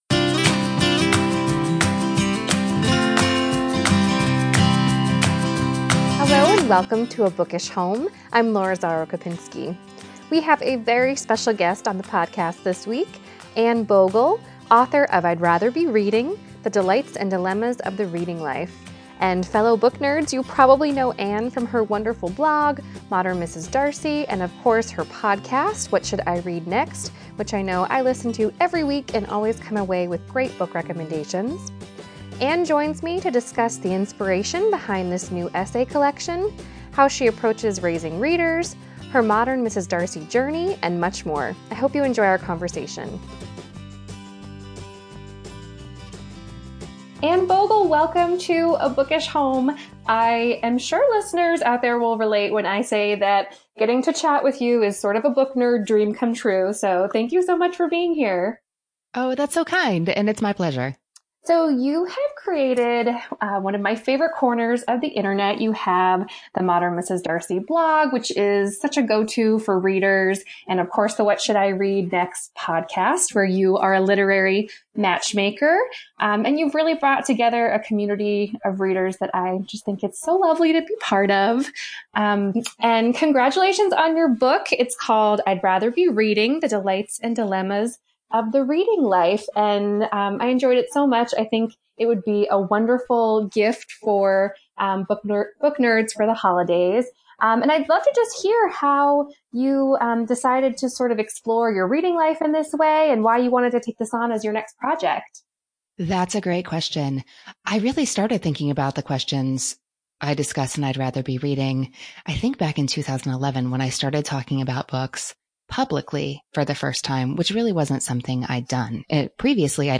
I hope you enjoy our conversation! https